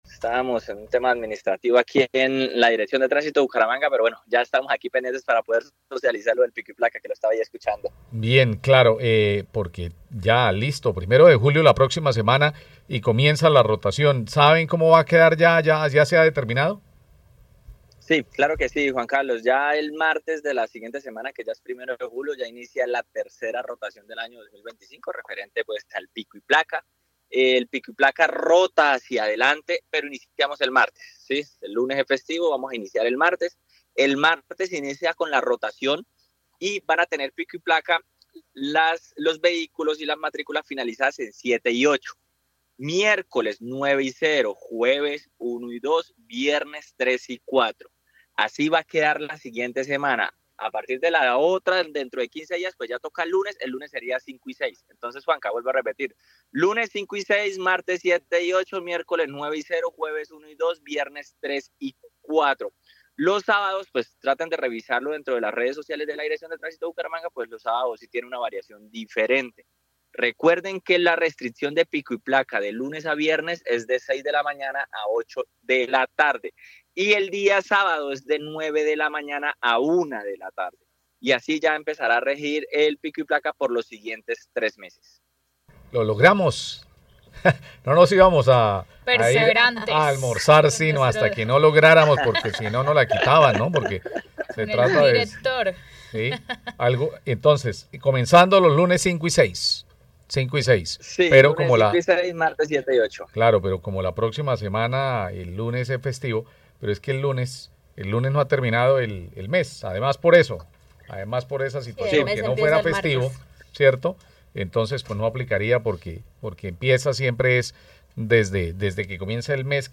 Jahir Manrique, director de Tránisto de Bucaramanga anuncia rotación del pico y placa